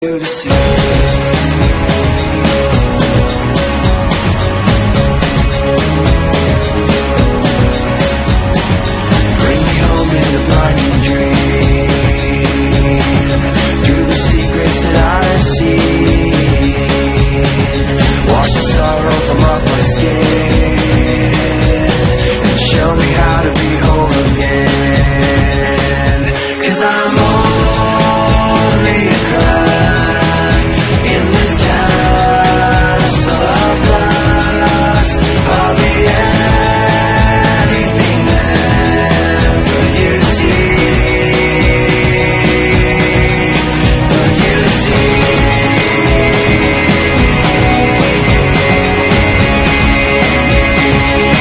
Electrónica